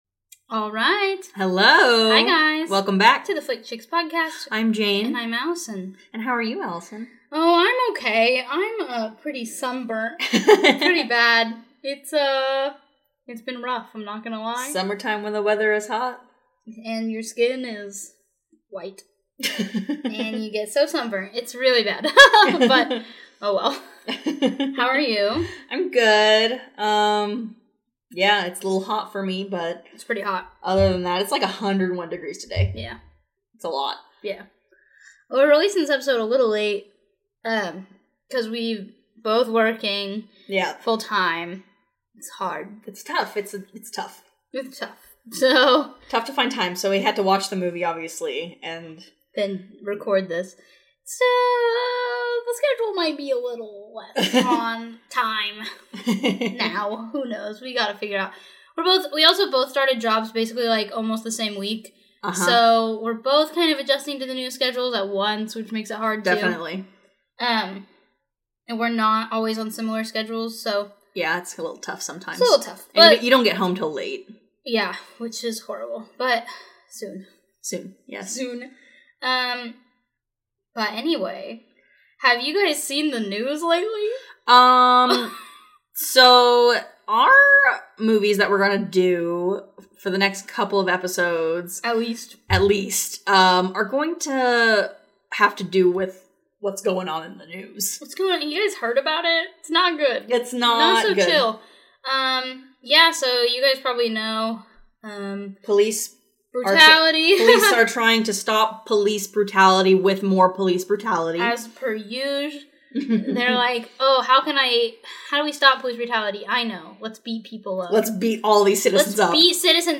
DISCLAIMER: We are both white females.